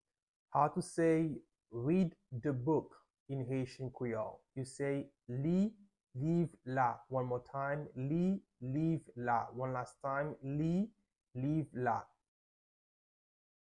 Pronunciation:
12.How-to-say-Read-the-book-in-Haitian-Creole-–-Li-liv-la-with-Pronunciation.mp3